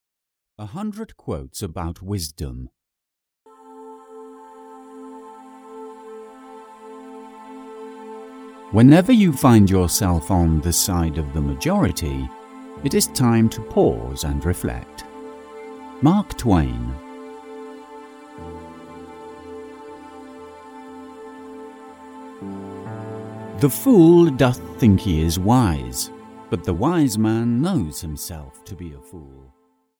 100 Quotes About Wisdom (EN) audiokniha
Ukázka z knihy